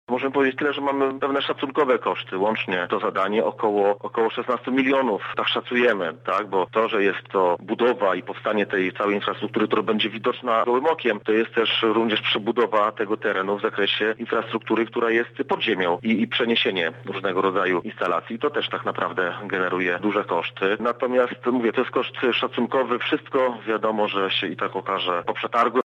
Dla mieszkańców wyjazd z osiedla sprawiał czasem dużo problemów – mówi Wójt Gminy Wólka, Edwin Gortat: